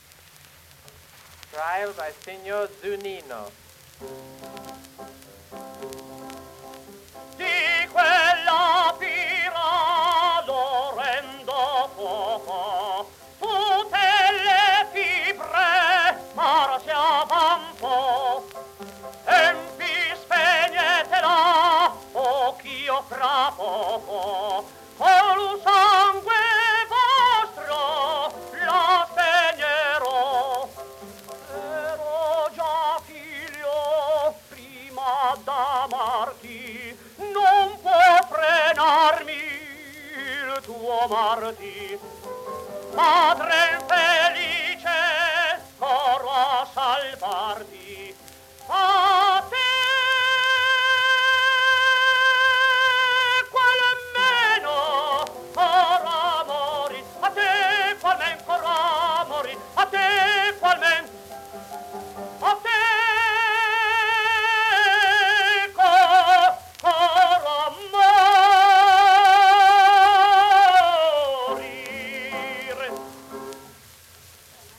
It was recorded in 1912 in Milano: